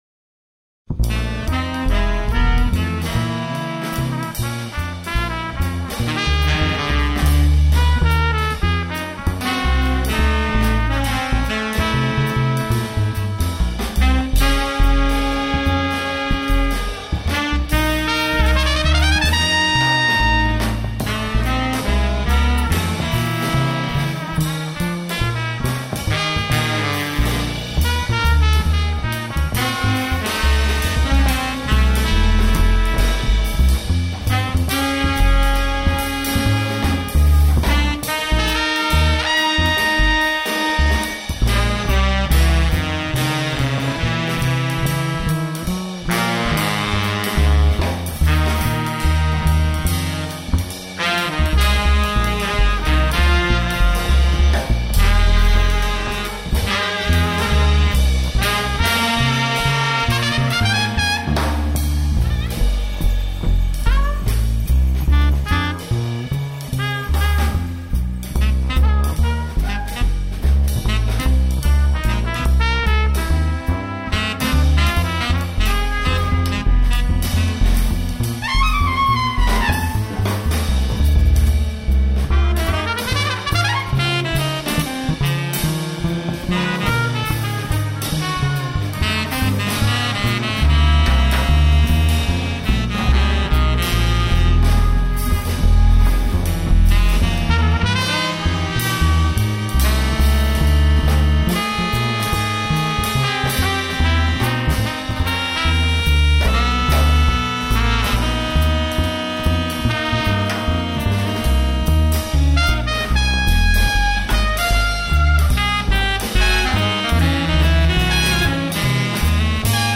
tromba e percussioni
saxofoni
batteria
contrabbasso